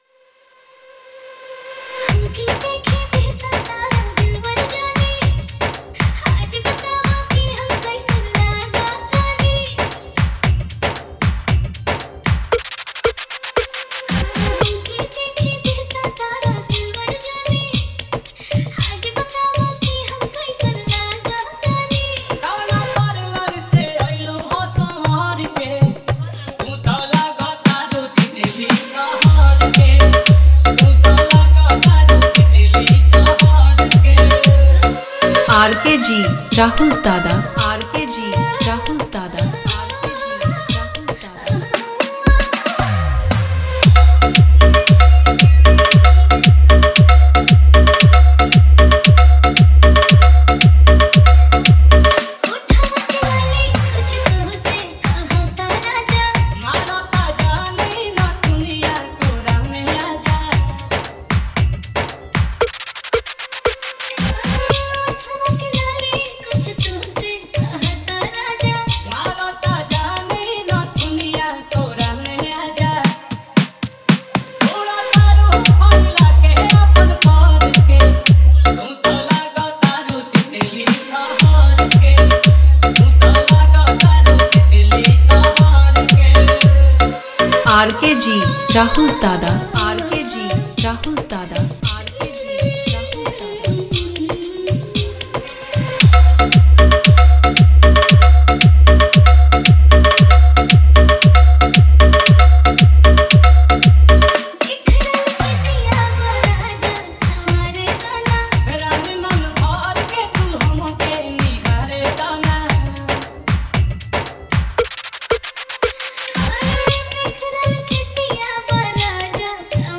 Bhojpuri Remix Songs